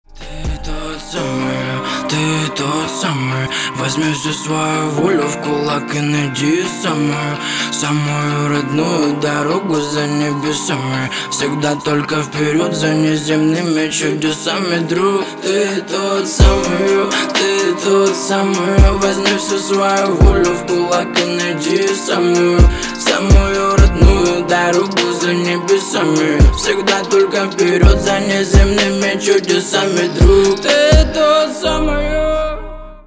• Качество: 320, Stereo
мужской голос
русский рэп
мотивирующие
спокойные
качающие